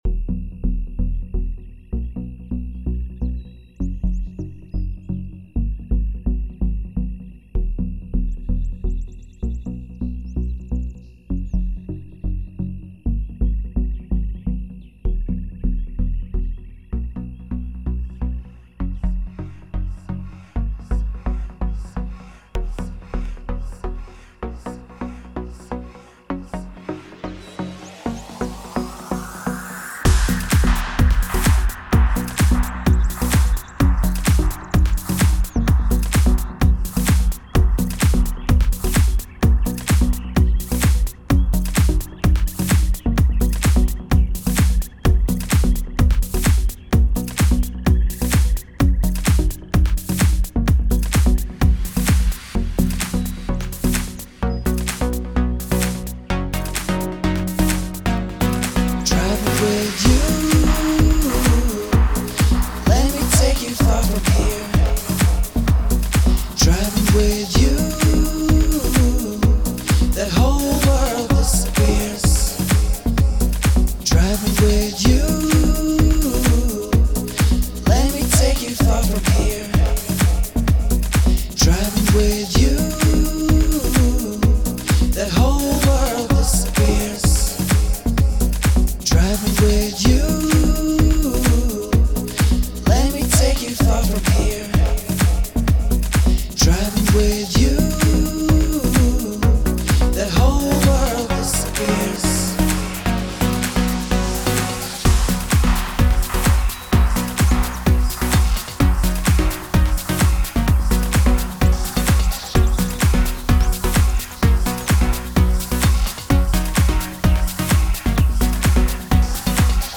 Deep House House Pop Progressive House Tropical House